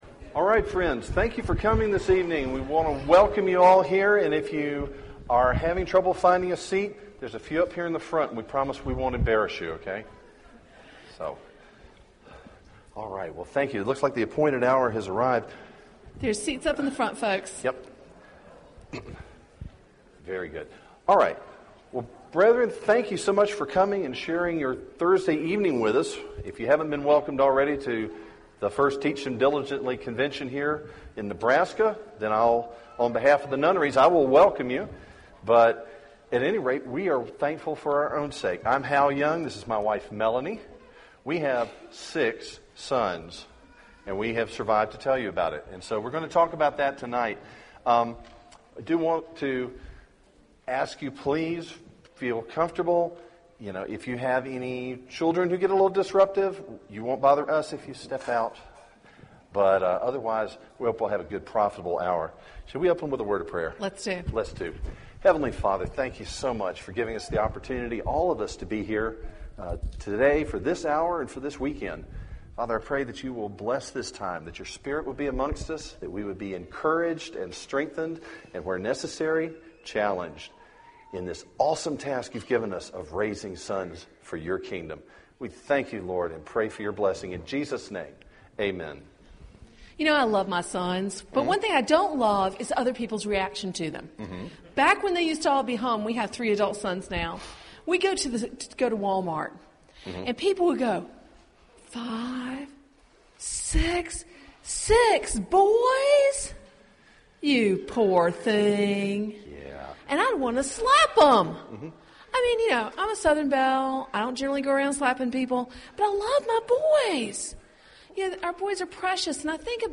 Click here to listen to our workshop on raising boys to be godly men.